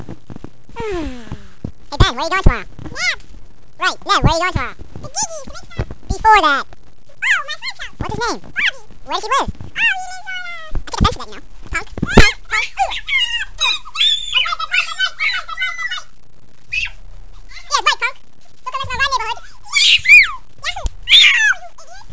It is suspected that this chipmunk is indeed the chipmunk in the audio clips, probably the one with the lower voice.